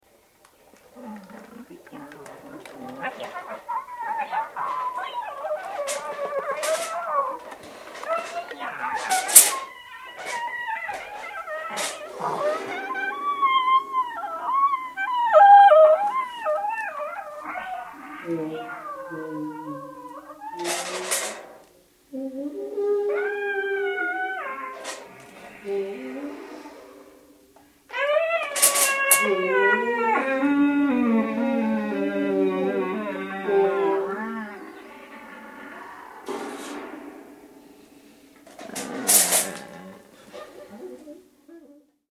- quelques séquences improvisées, avec instruments multiples (
flûtes, sax soprano, voix
feuilles, flûtes, violon, bruits, percussions multiples